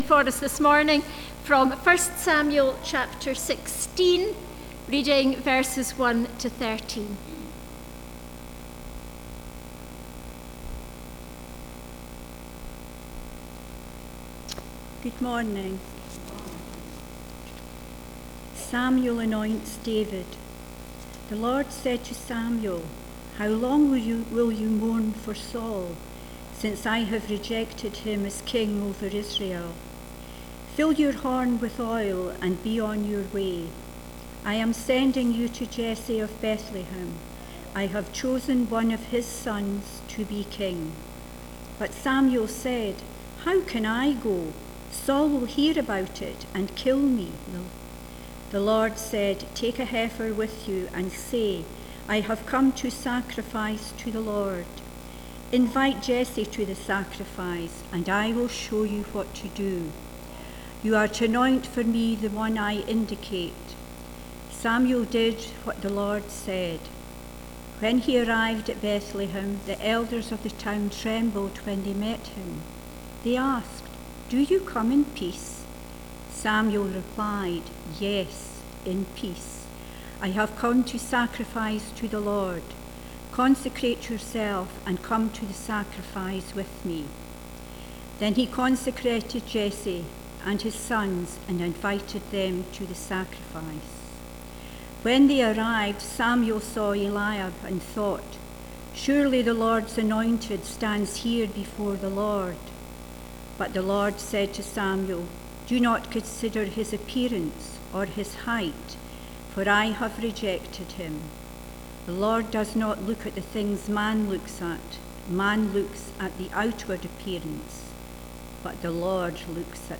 The Life of King David Passage: 1 Samuel 16:1-13 Service Type: Sunday Morning « “Go and make disciples” David and Goliath »